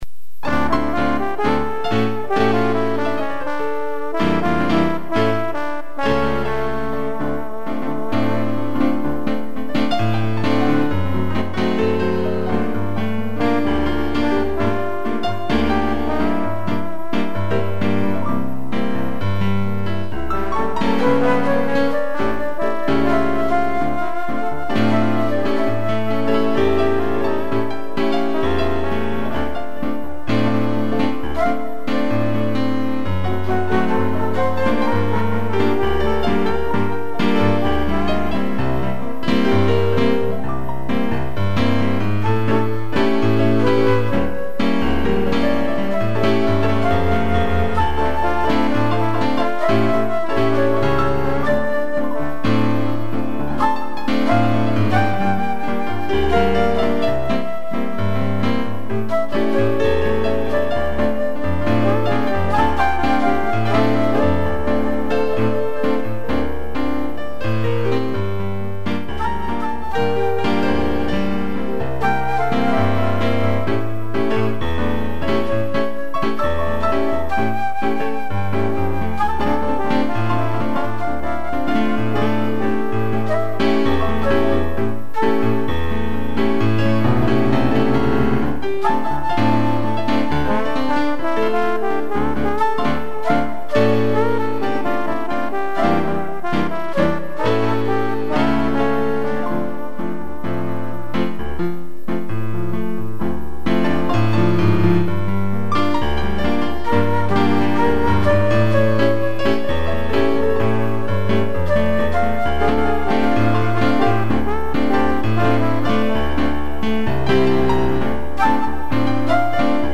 2 pianos, trombone e flauta
(instrumental)